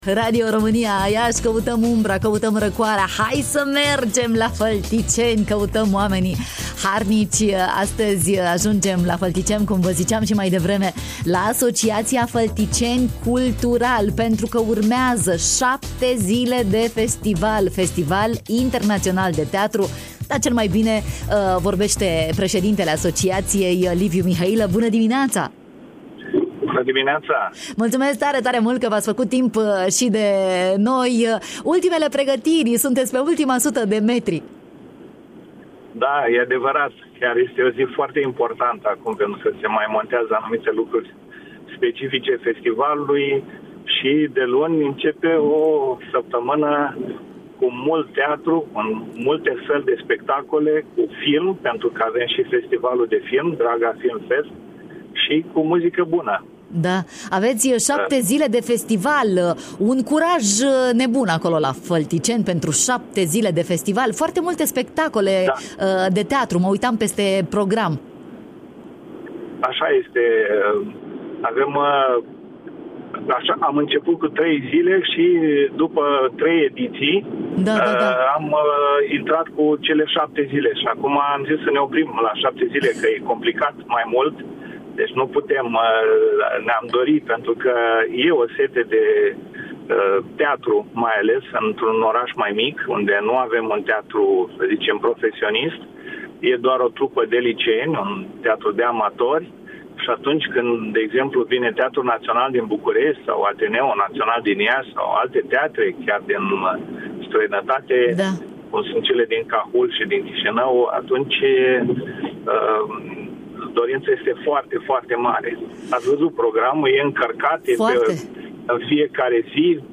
în direct la matinalul de la Radio Iași